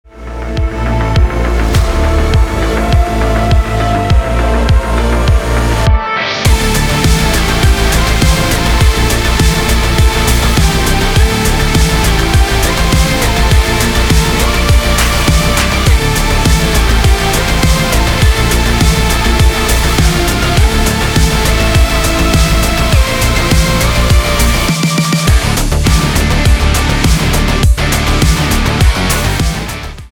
электроника
инструментальные , без слов
басы
гитара , барабаны